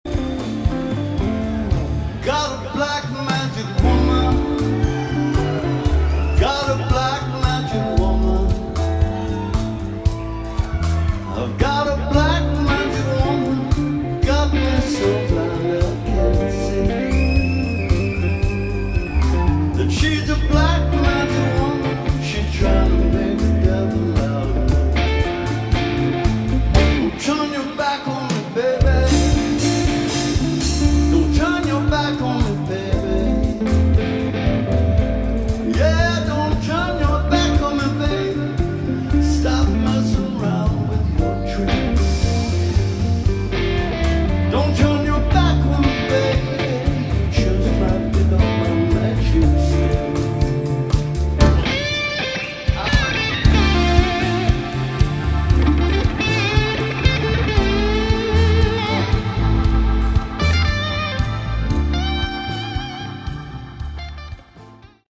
Category: Melodic Rock / AOR
guitar
keyboards, vocals
bass
drums, vocals